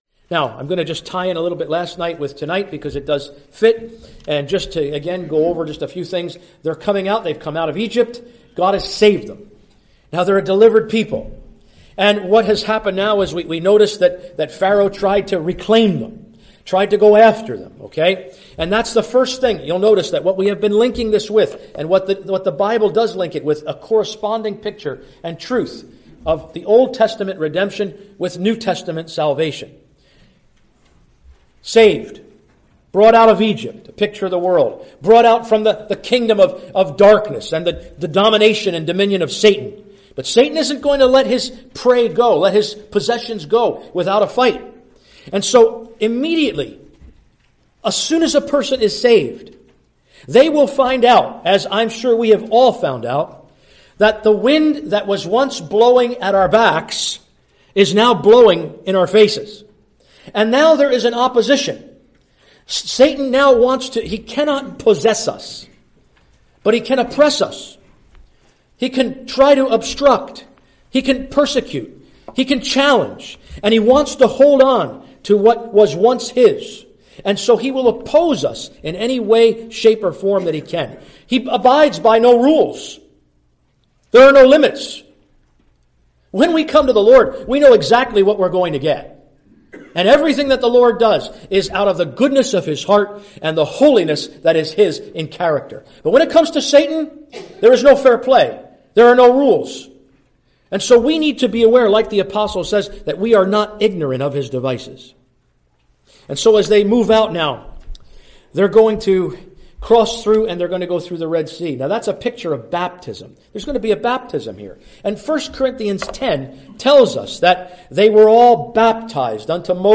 (Recorded in Marion Gospel Hall, Iowa, USA)